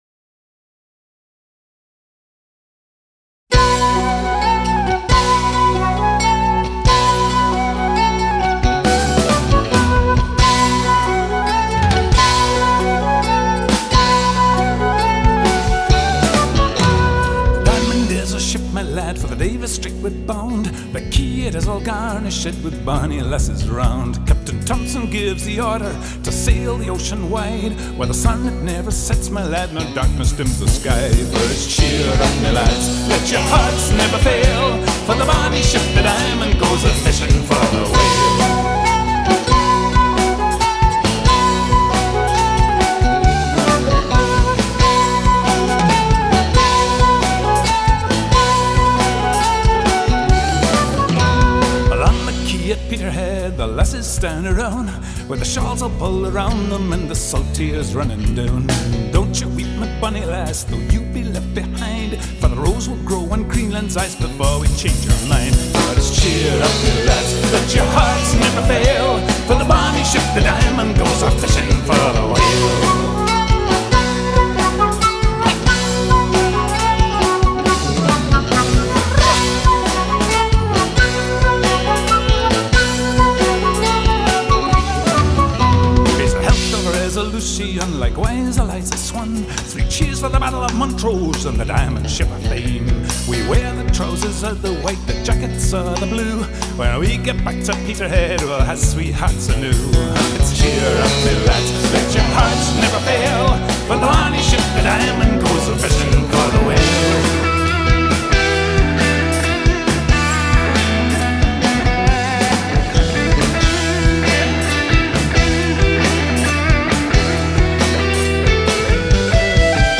accordeon
Drum